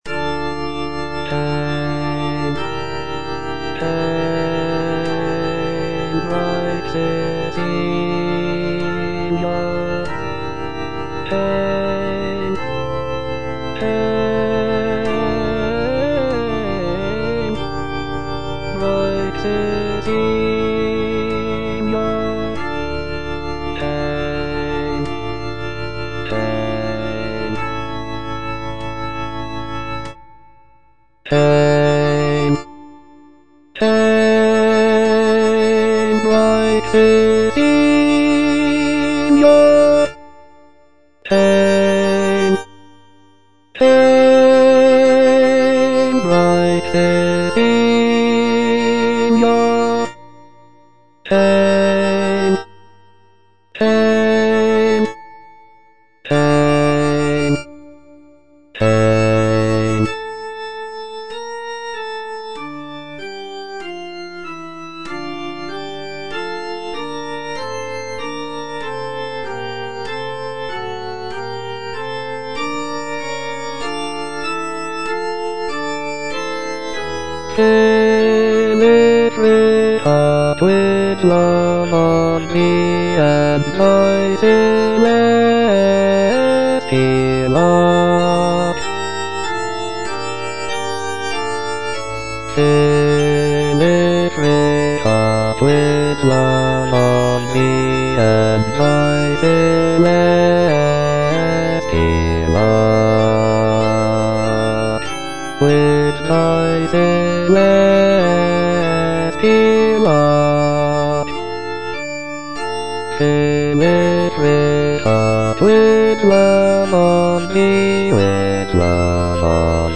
H. PURCELL - ODE FOR ST. CECILIA’S DAY, 1692 Hail, bright Cecilia (I) - Bass (Voice with metronome) Ads stop: auto-stop